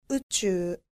• うちゅう
• uchuu